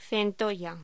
Locución: Centolla
voz